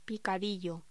Locución: Picadillo
voz